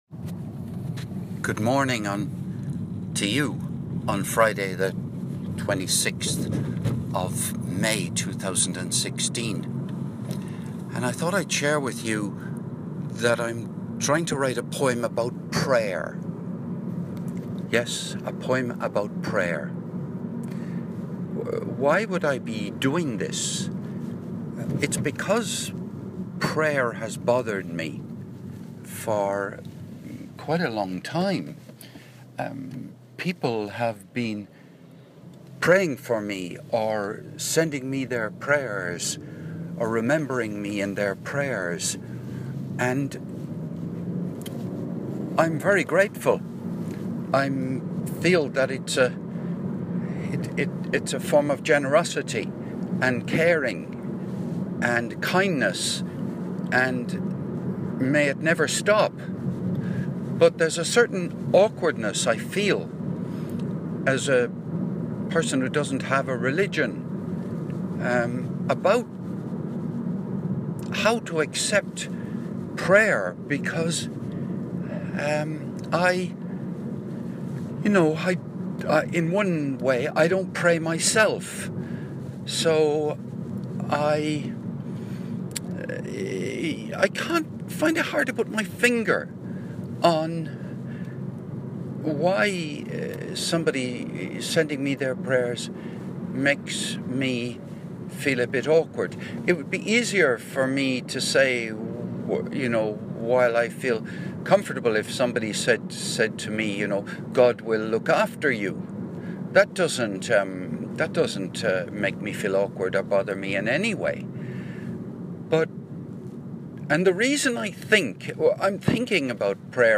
Audio made while composing a poem on the theme of "prayer".